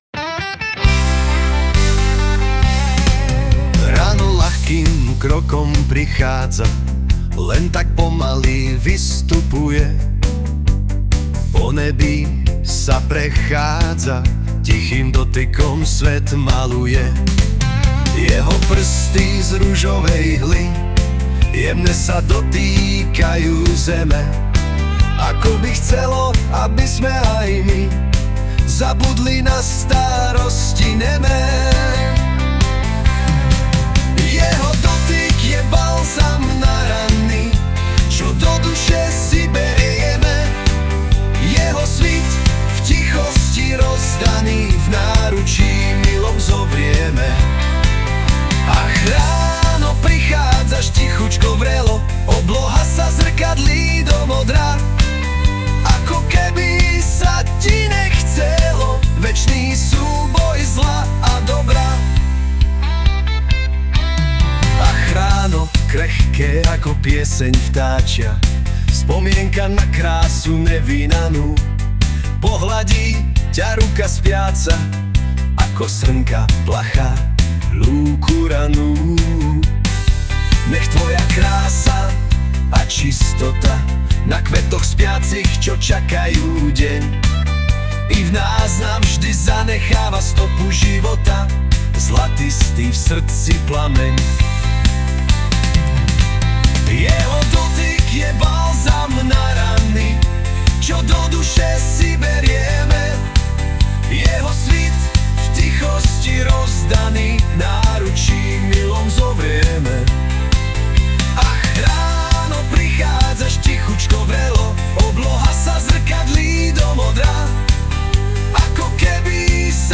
Anotace: Hudba a spev AI